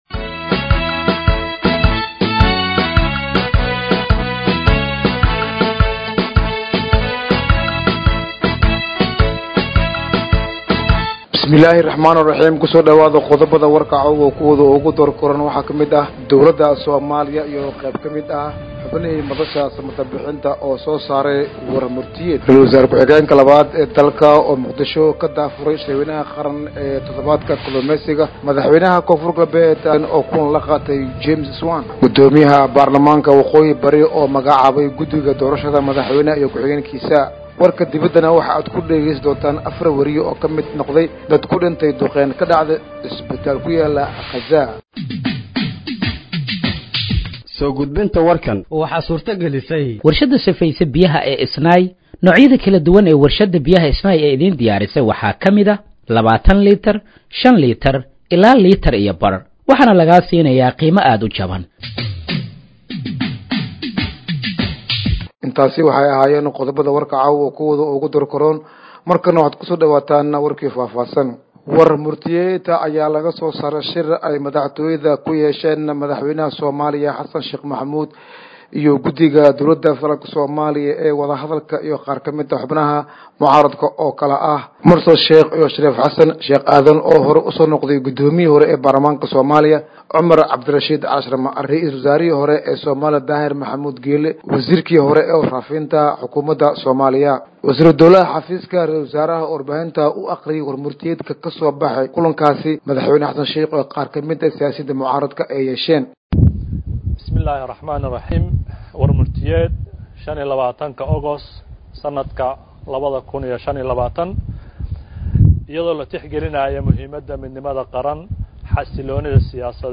Dhageeyso Warka Habeenimo ee Radiojowhar 25/08/2025